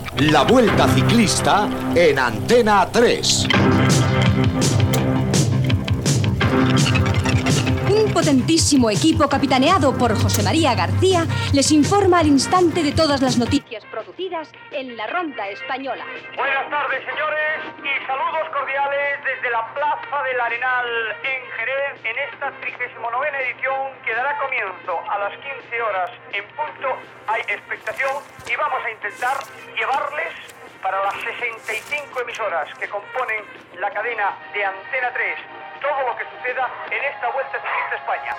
Careta del programa, salutació des de Jerez de la Frontera en l'inici de la 39 Vuelta Ciclista a España.
Esportiu